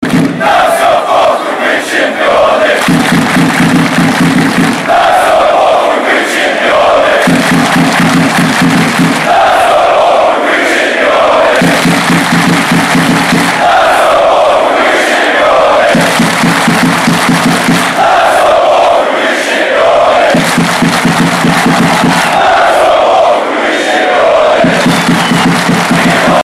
• Качество: 278, Stereo
футбольные кричалки